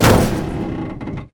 car-metal-impact-3.ogg